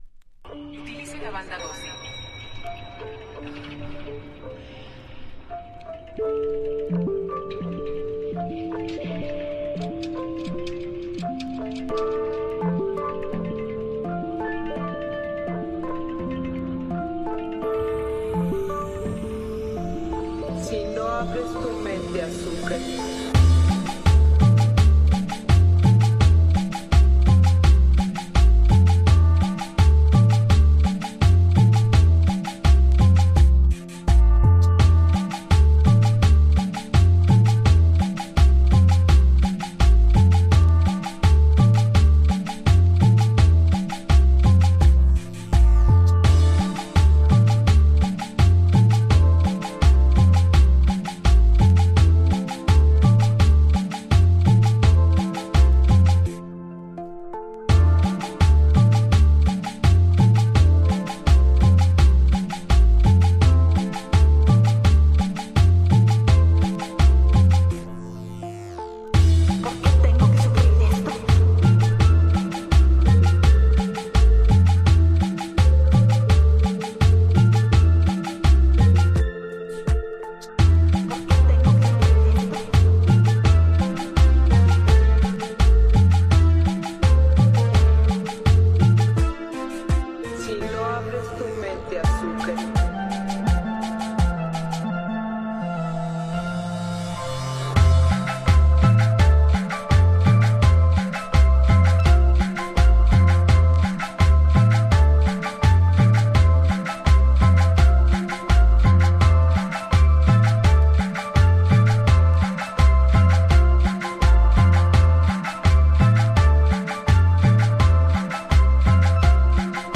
Tags: Cumbia , Sonidero